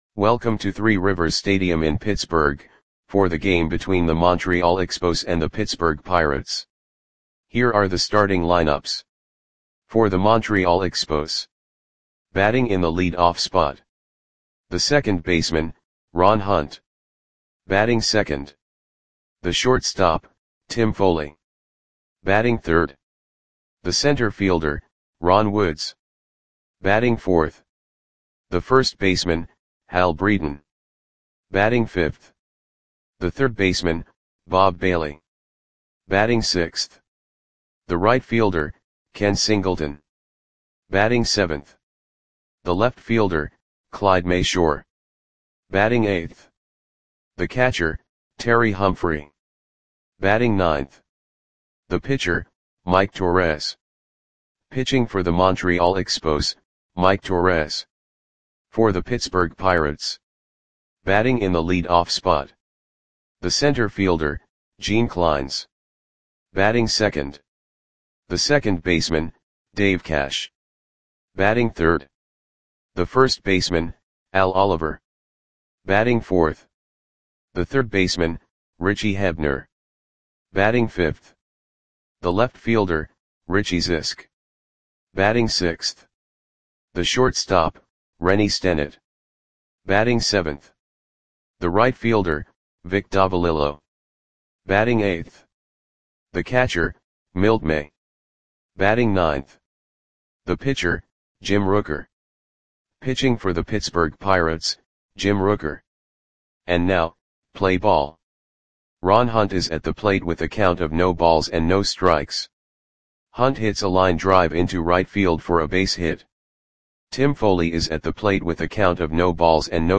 Lineups for the Pittsburgh Pirates versus Montreal Expos baseball game on July 1, 1973 at Three Rivers Stadium (Pittsburgh, PA).
Click the button below to listen to the audio play-by-play.